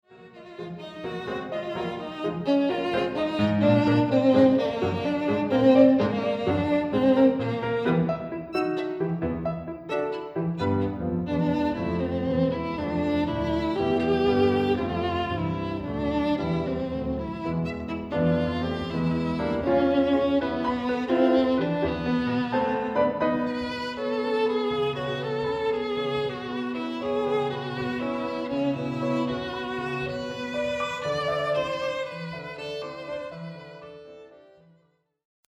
Sonata for violin and piano
Classical, Cross-cultural